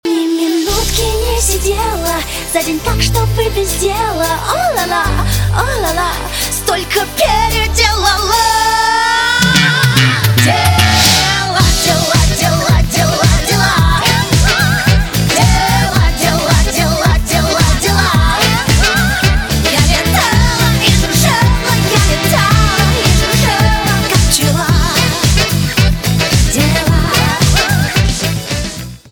русский рок
труба
гитара , барабаны